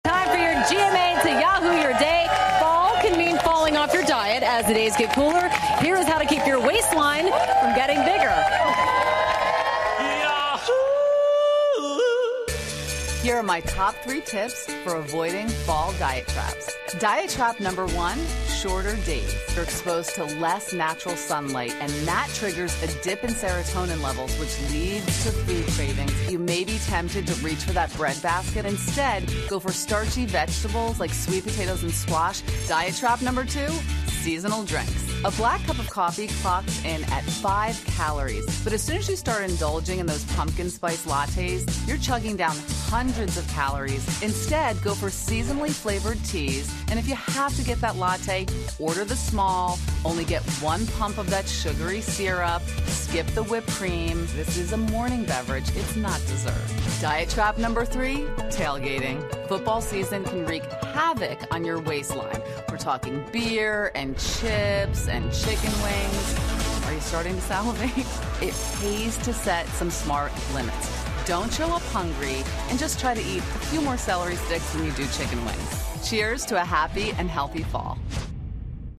访谈录 秋季饮食误区 三个陷阱莫误入 听力文件下载—在线英语听力室